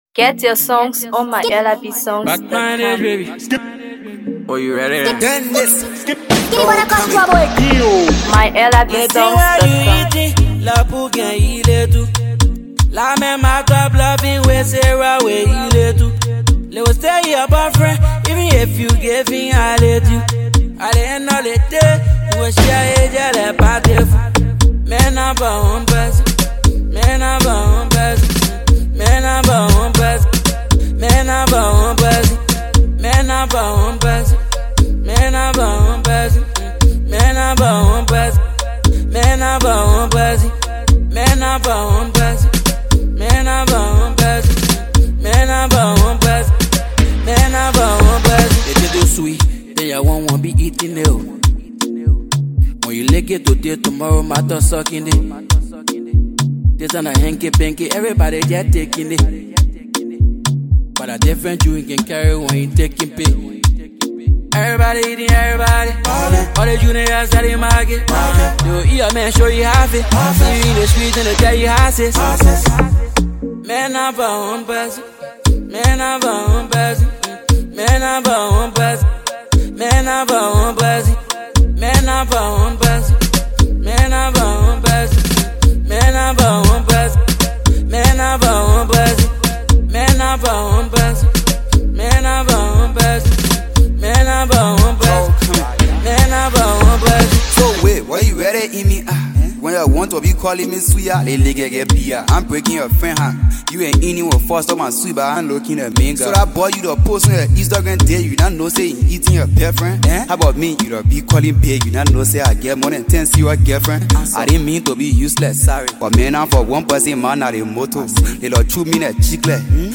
Afro PopMusic
With emotive vocals and intricate instrumentals